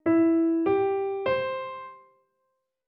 Rozpoznawanie trybu melodii (smutna,wesoła)